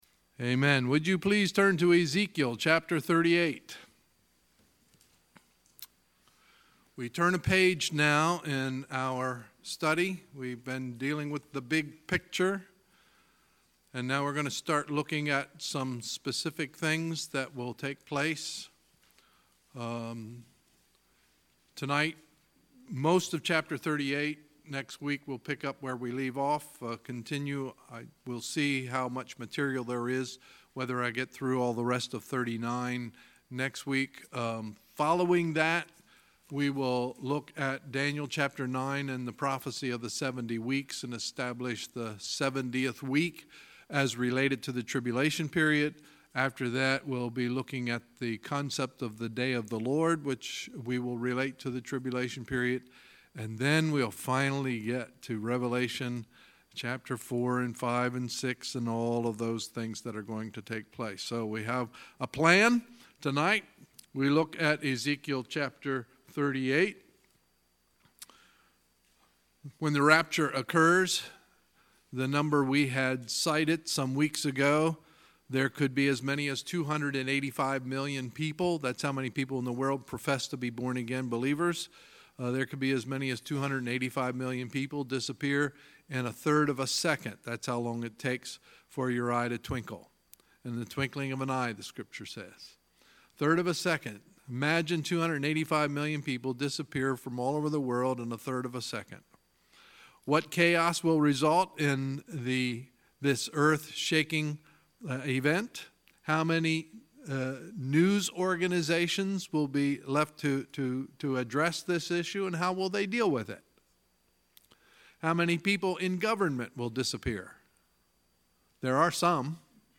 Sunday, September 2, 2018 – Sunday Evening Service
Sermons